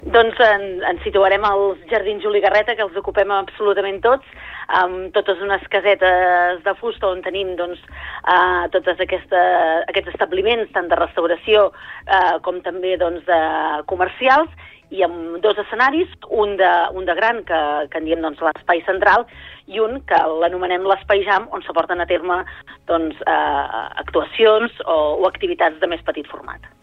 Al Supermatí hem parlat amb la regidora de Promoció econòmica de l’Ajuntament del municipi, Núria Cucharero, per parlar de la proposta i de les activitats que podrem trobar durant els tres dies de fira.